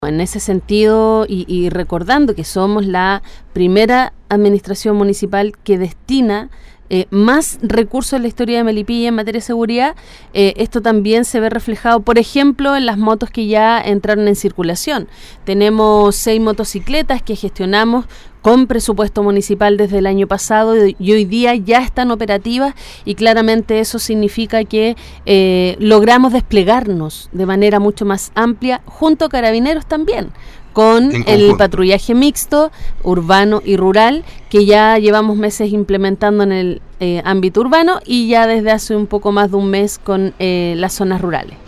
Durante una entrevista realizada en “La Mañana de Todos” de Radio Ignacio Serrano, la alcaldesa Olavarría respondió a las dudas  más importantes para la comuna de Melipilla